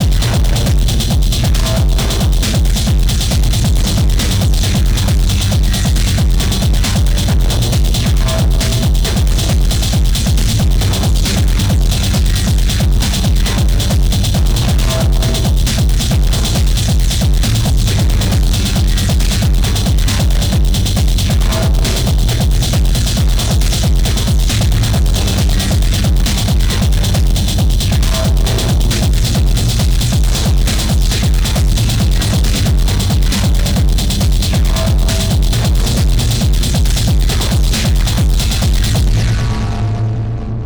Those last two are some serious early AFX vibes.